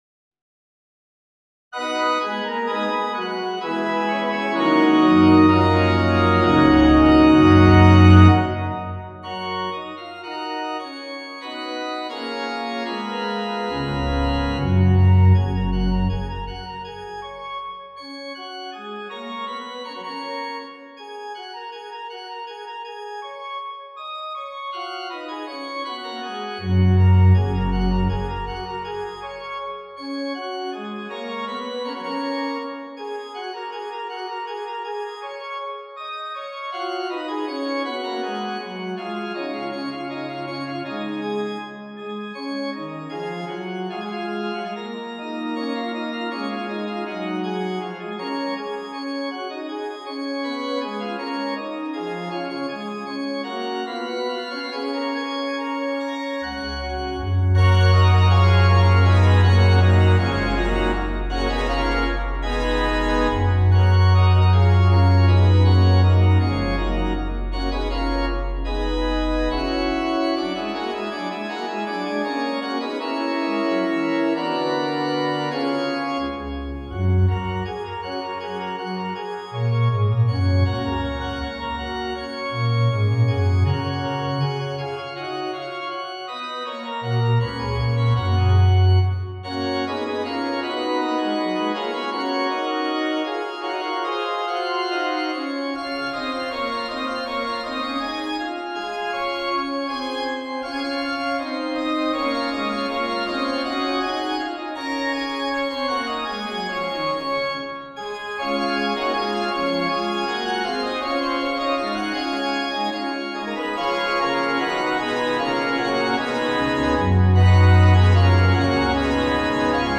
for organ The two-measure gesture is repeated phrases is spun out with counterpoint and parallel episodic gestures.
Fugue in F sharp.mp3